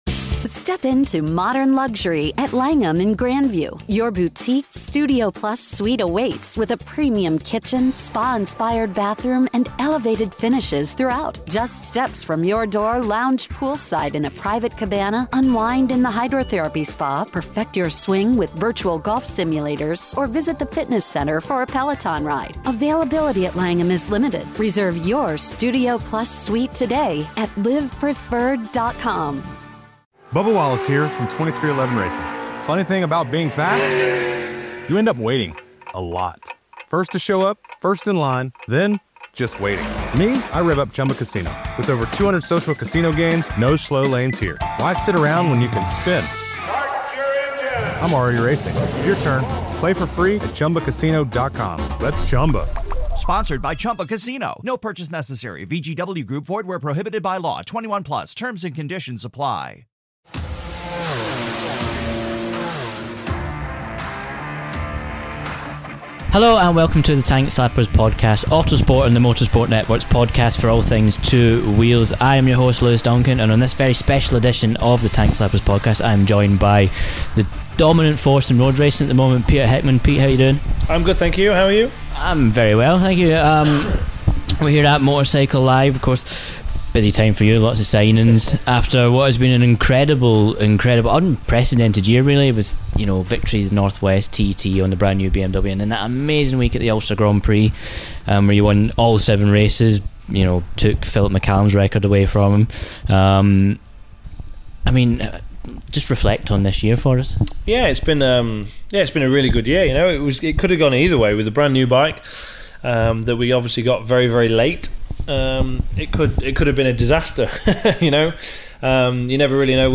Interview: Road Racing Hero Peter Hickman - Episode #15